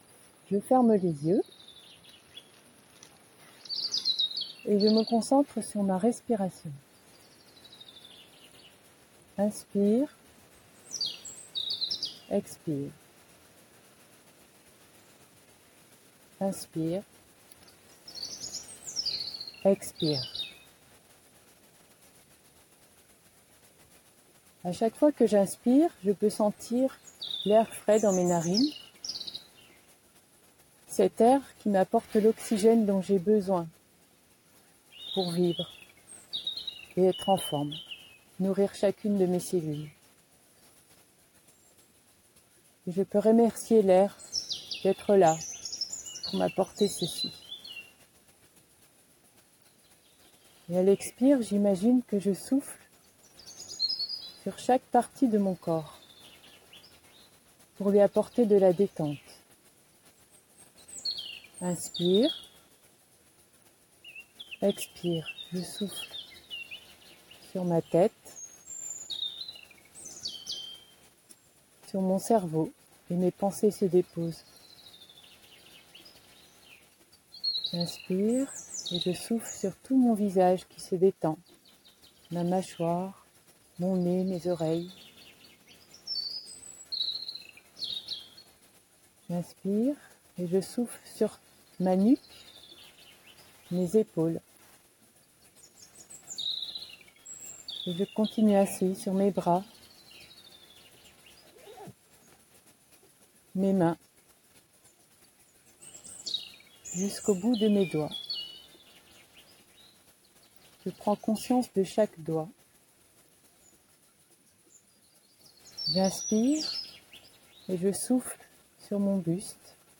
Méditation guidée en forêt
Meditation-en-foret-reliance-ecosysteme-juin-2025.m4a